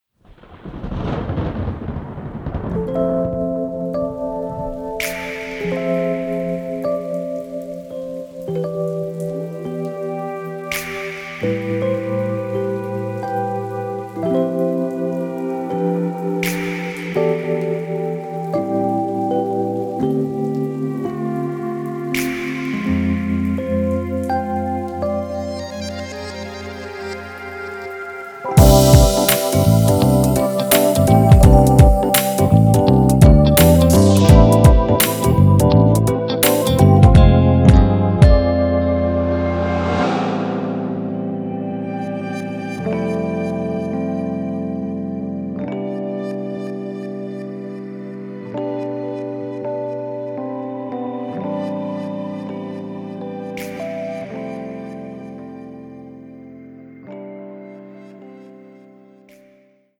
歌曲调式：C大调